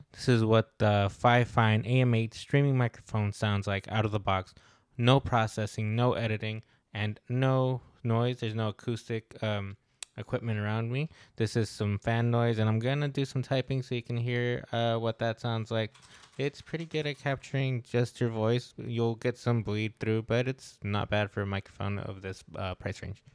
Out of the box and without any processing, you can get a solid sound from this cool looking device. I am very impressed with how good at is at filtering background noise. There will be some bleed, but it is far better than other mics at a similar price point.